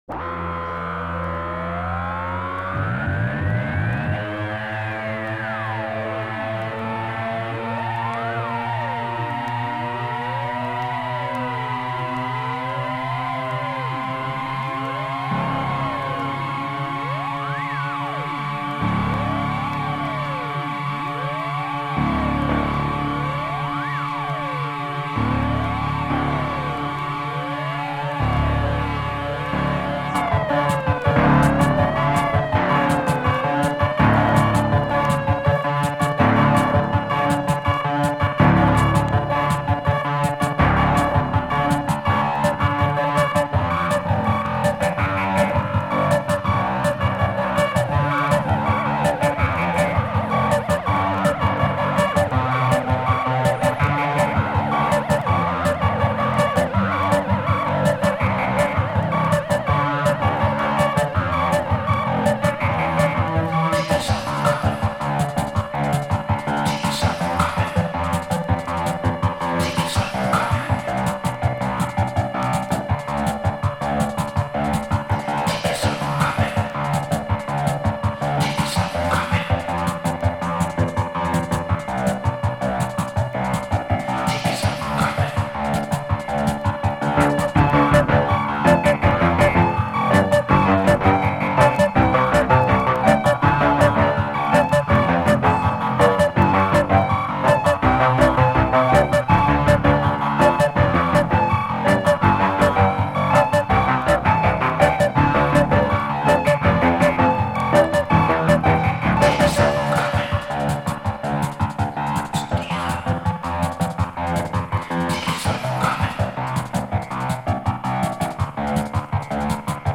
Avant-Garde Electronic